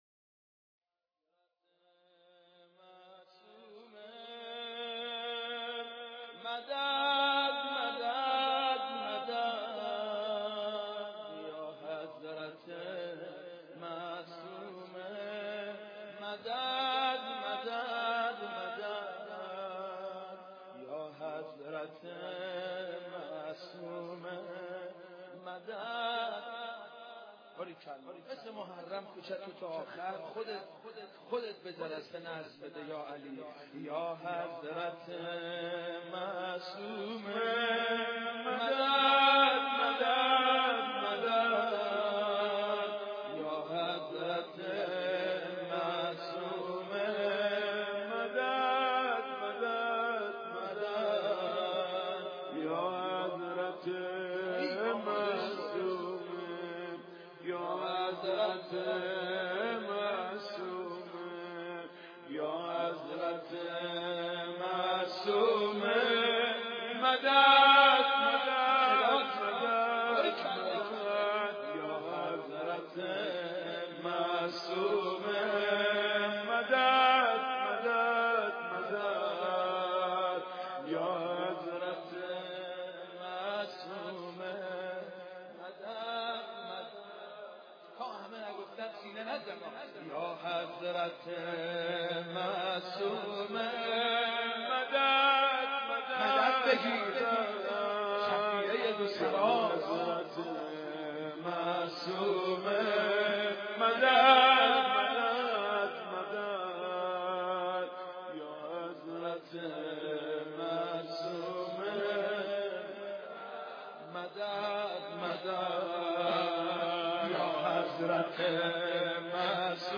مداحی | پایگاه اطلاع رسانی آستان مقدس حضرت عبدالعظیم الحسنی علیه السلام